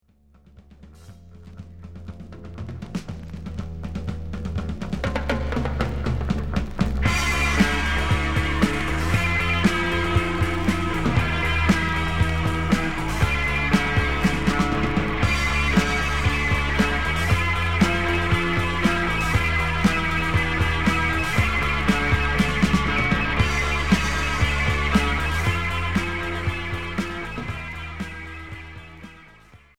Alternatif